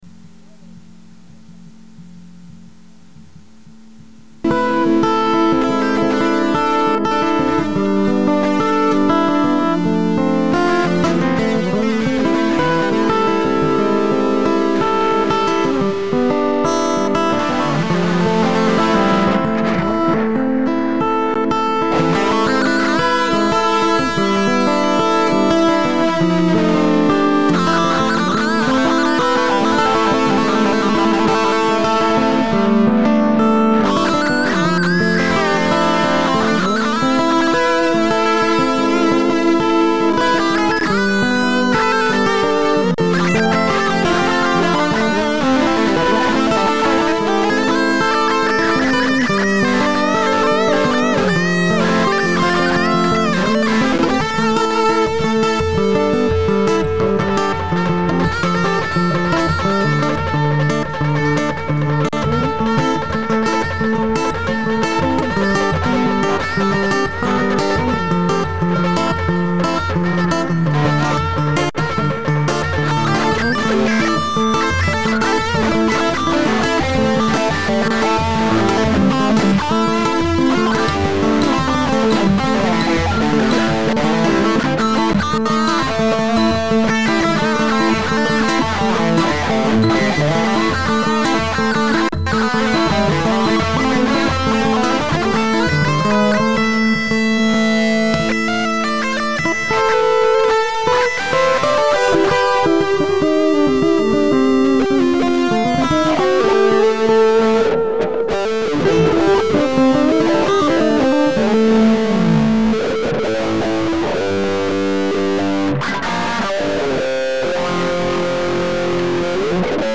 Jams